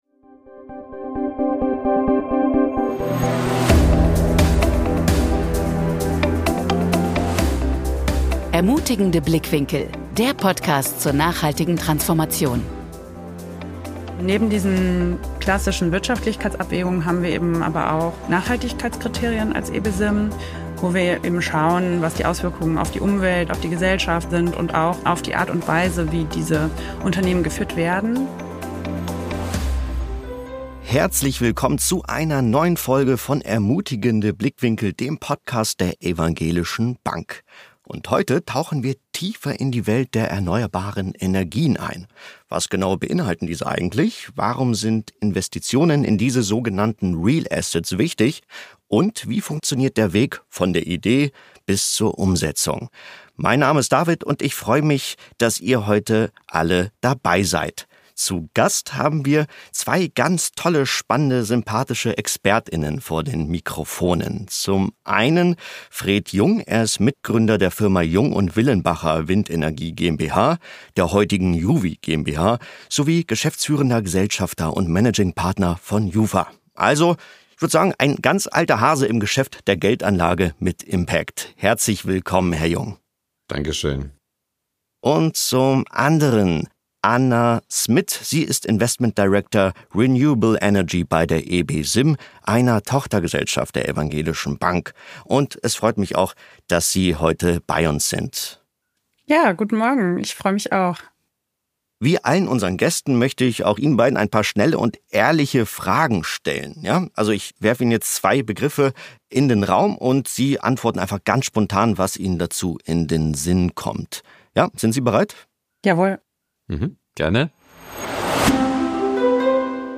In einem Gespräch